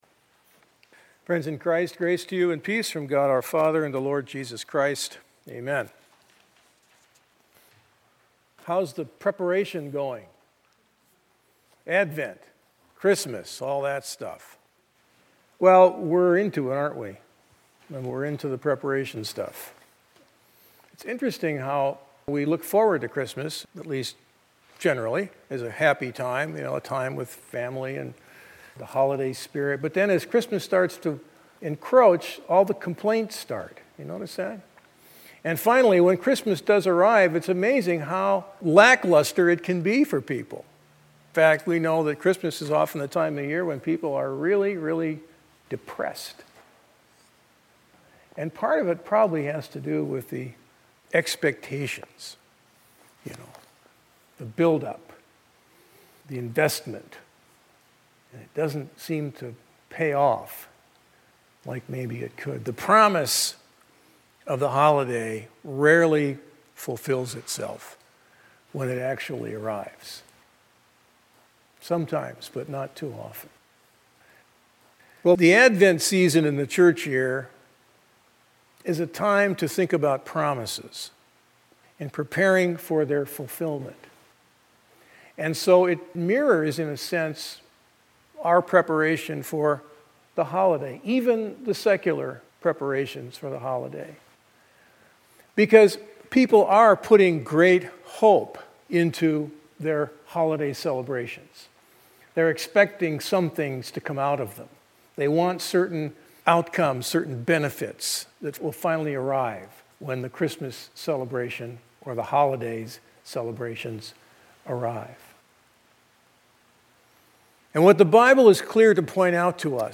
advent-and-christmas-expectations.mp3